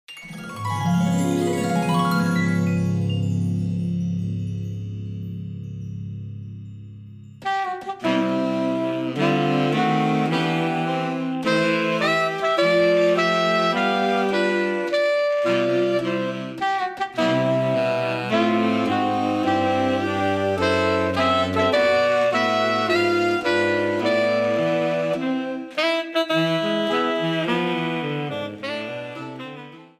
Shortened, applied fade-out and converted to oga